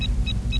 beep4.wav